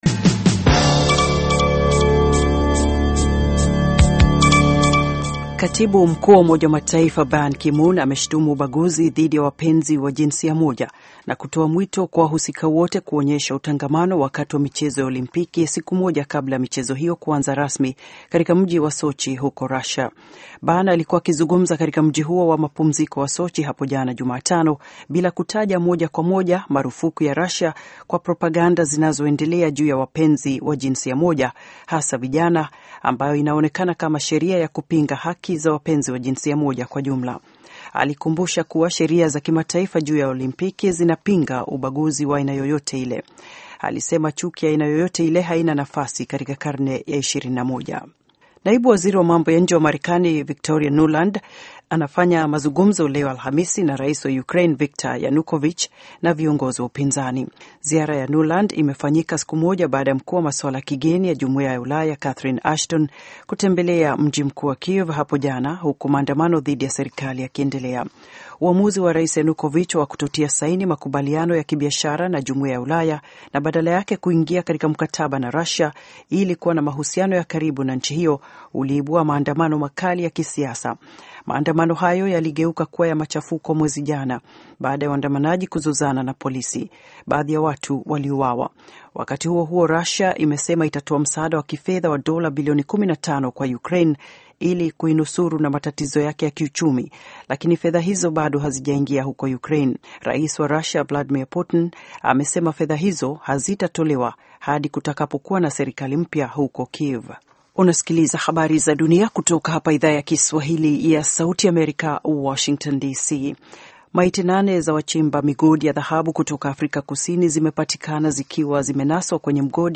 Taarifa ya Habari VOA Swahili - 6:30